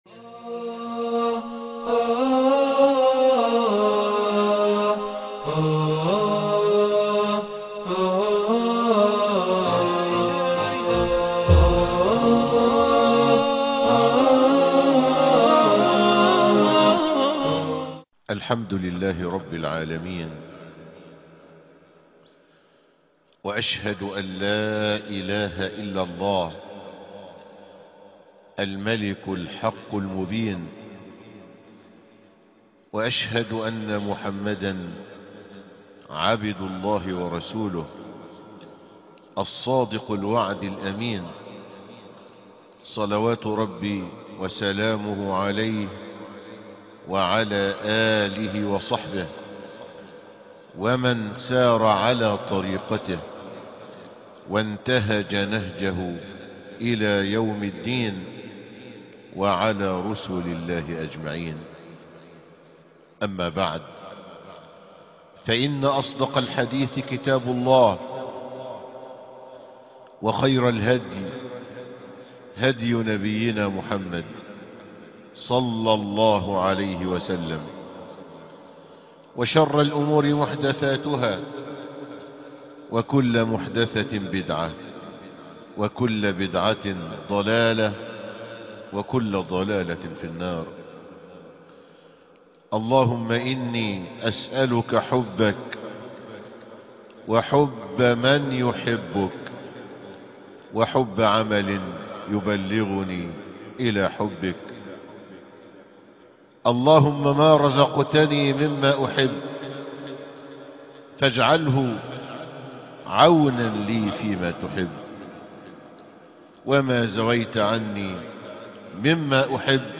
ثمرات محبة الله لك خطبة رقم 03 خطب الجمعة